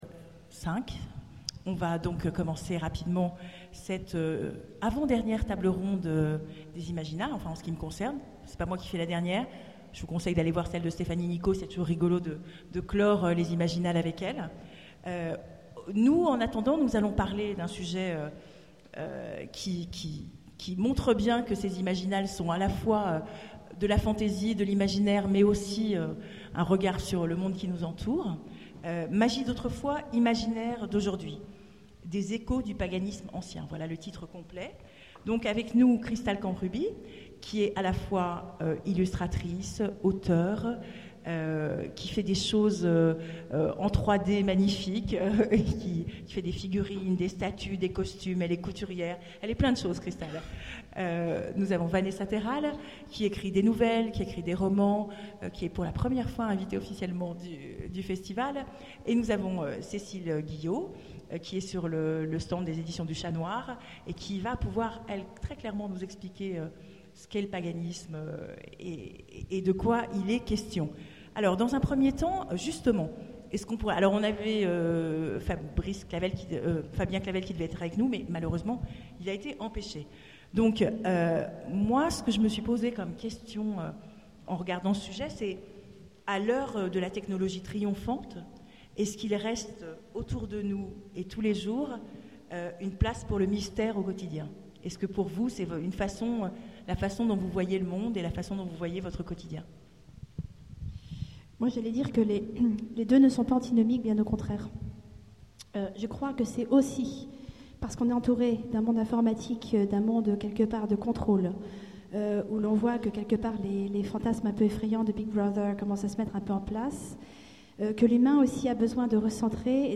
Imaginales 2014 : Conférence Magie d'autrefois, imaginaires d'aujourd'hui...